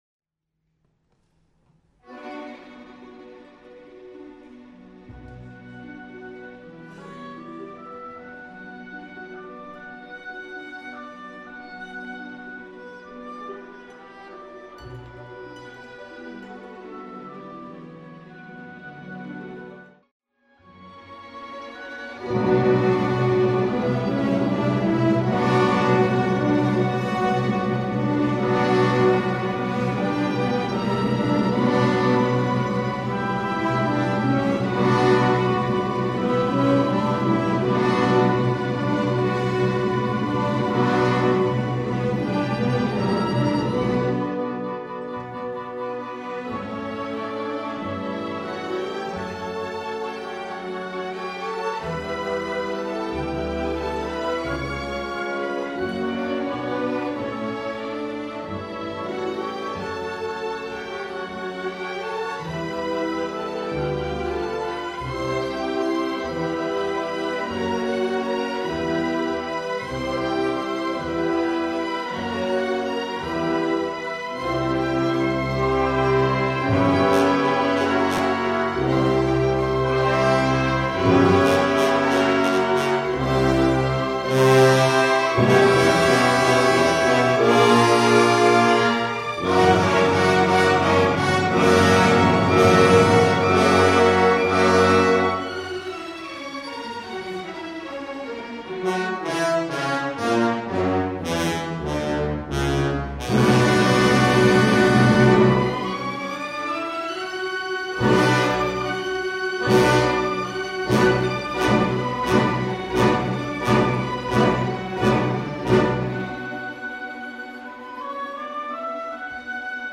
Presbyterian Church of Los Gatos: 16575 Shannon Rd.
2. Valse
5. Hungarian Dance-Czardas
8. Mazurka